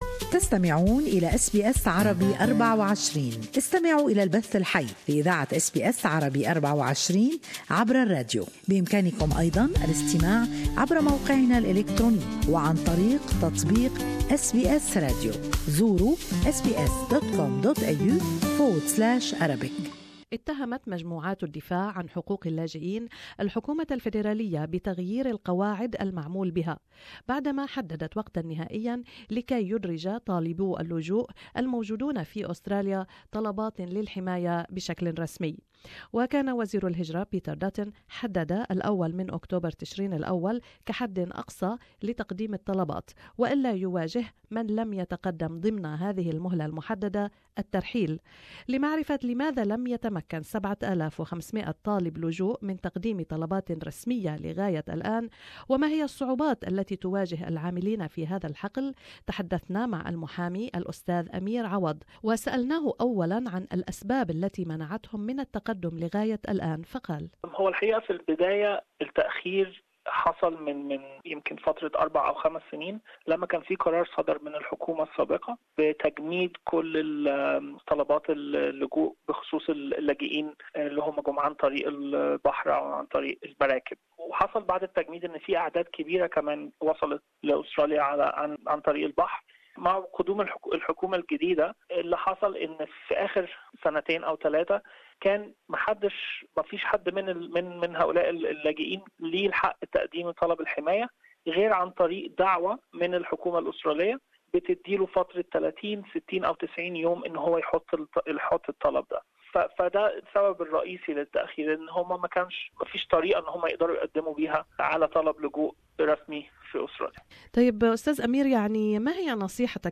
Advocacy groups are accusing the Federal Government of changing the rules after setting a deadline for thousands of refugee applications from asylum seekers now in Australia. Immigration Minister Peter Dutton has set what he calls a non-negotiable cut-off date of October the 1st for asylum seekers to make protection claims or face the threat of deportation. Interview